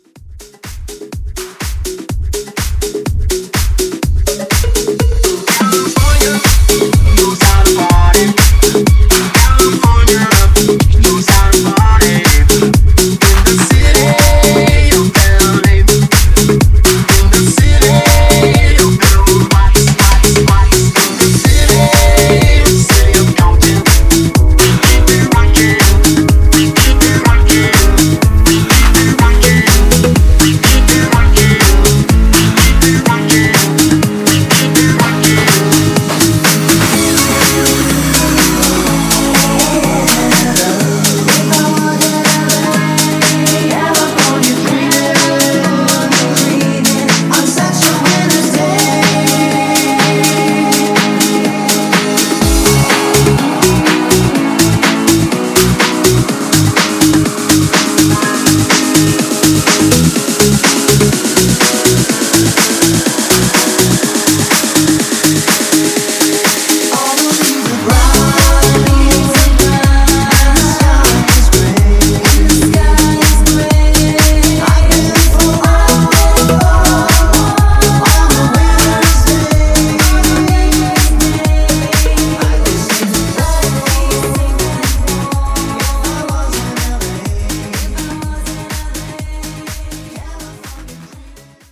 BPM: 124 Time